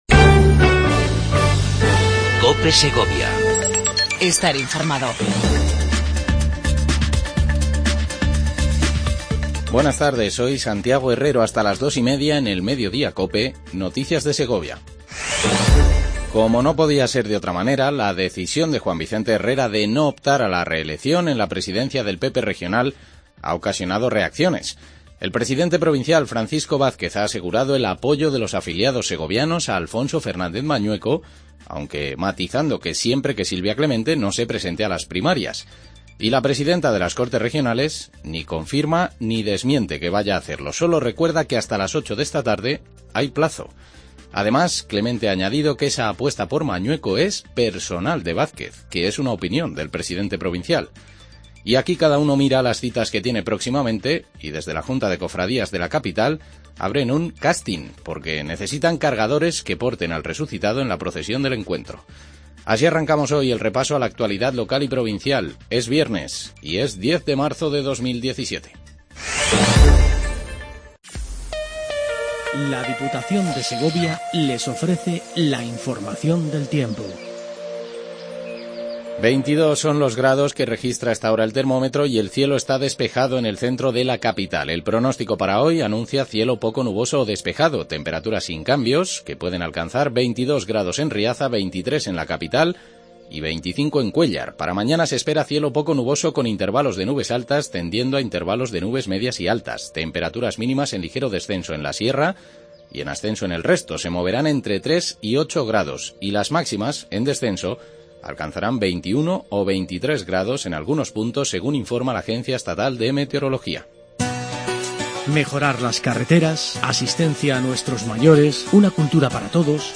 INFORMATIVO MEDIODIA COPE EN SEGOVIA